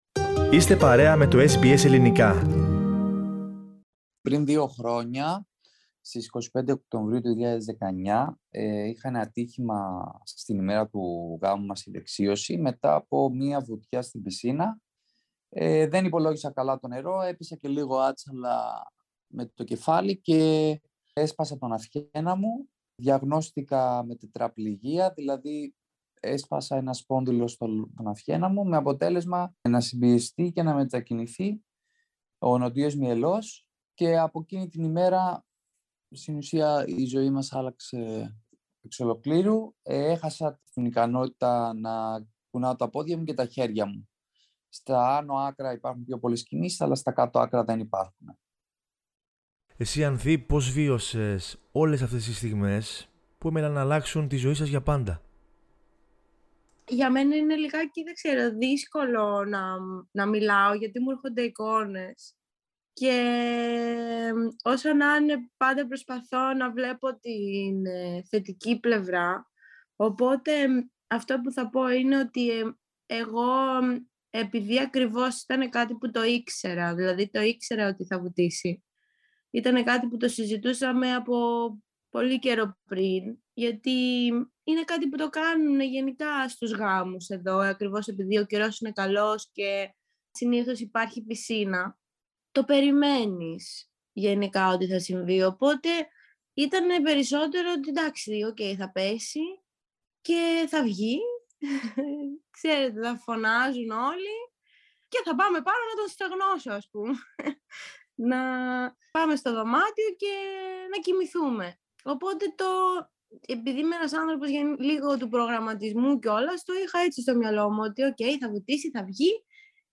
Κύρια Σημεία Τραυματίστηκε στον αυχένα την ημέρα του γάμου τους Στο κανάλι τους στο ΥouTube παρουσιάζουν τη ζωή τους Ο αγώνας για ζωή και το όνειρο των Παραολυμπιακών Αγώνων Το νεαρό ζευγάρι μίλησε στο Ελληνικό Πρόγραμμα της Ραδιοφωνία SBS, για την ημέρα που άλλαξε τη ζωή του για πάντα, το κανάλι τους στο ΥouTube μέσα από το οποίο παρουσιάζουν την καθημερινότητά τους, αλλά και το όνειρό τους να πραγματοποιήσουν τον «μήνα του μέλιτος», που τους στέρησε η μοίρα.